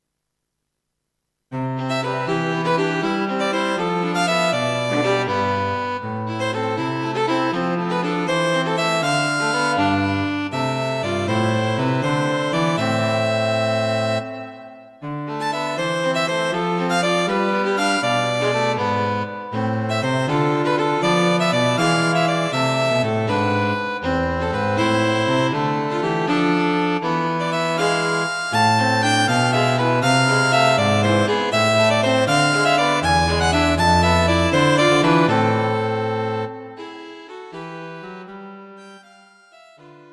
A lively, modern suite of three pieces for String Trio .
String Trio(Violin, Viola, Cello).